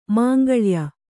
♪ māngaḷya